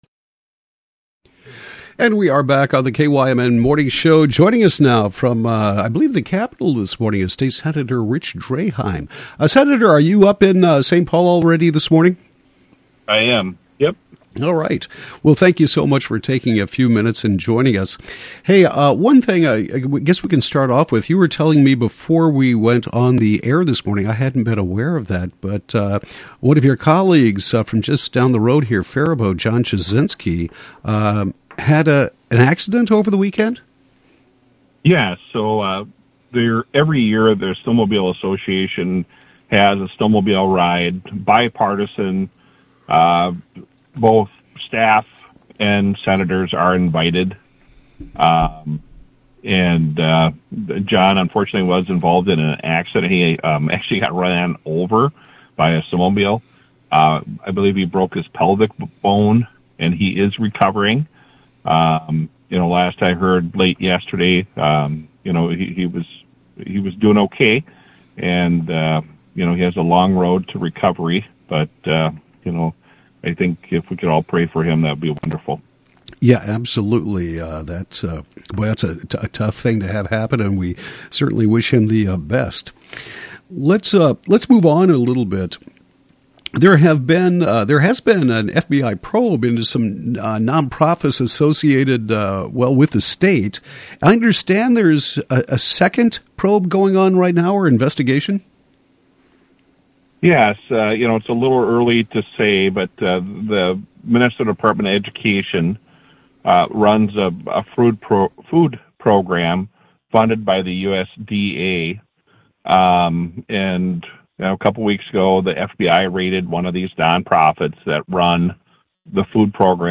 Senator Rich Draheim talks about an FBI probe into non-profit food programs associated that allegedly misused funds received during Covid, comments on the Workers' Compensation Bill signed by the Governor, and more.